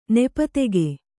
♪ nepa tege